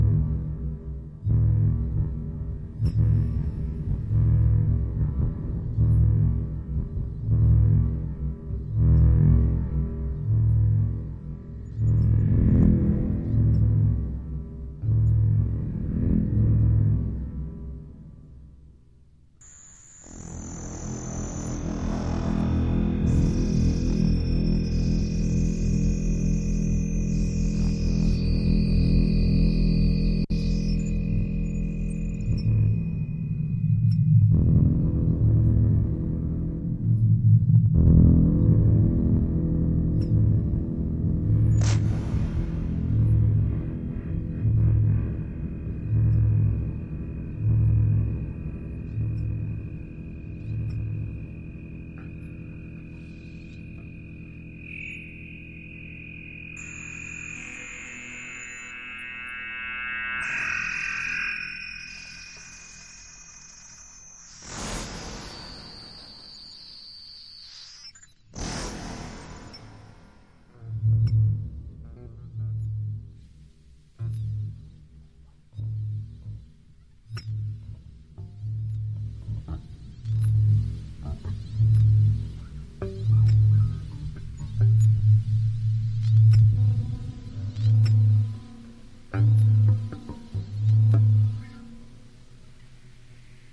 描述：原为贝斯和电子琴的加工作品
Tag: 反馈 低音的 偶然的 电子